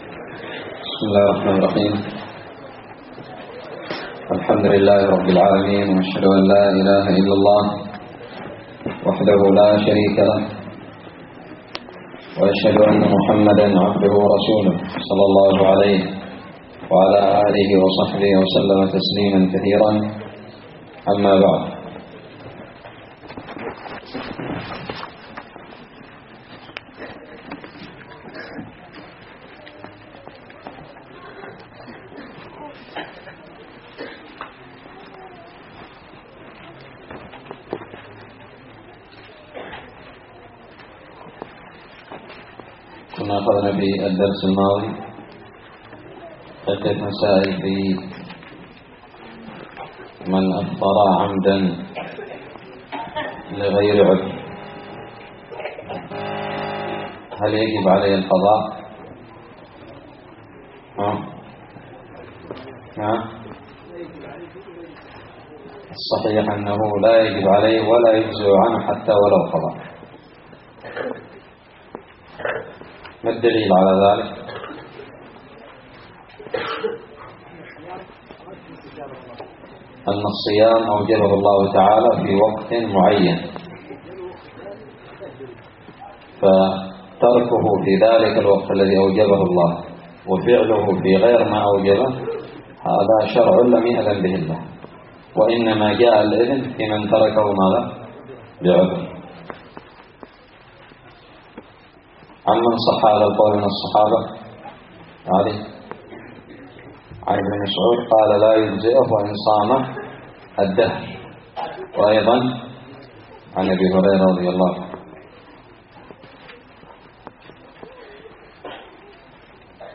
الدرس الحادي والعشرون من كتاب الصيام من الدراري
ألقيت بدار الحديث السلفية للعلوم الشرعية بالضالع